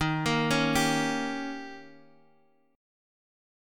D#7 chord